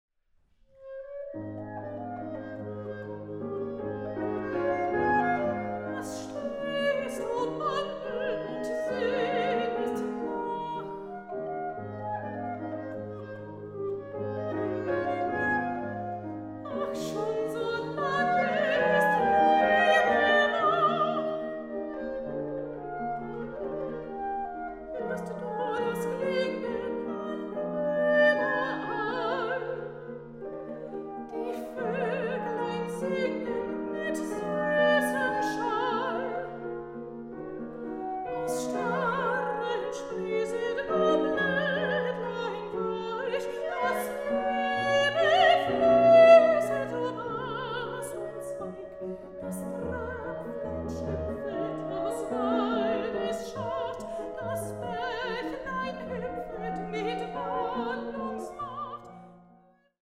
Klarinette
Klavier
Sopran
Aufnahme: Tonstudio Ölbergkirche, Berlin, 2023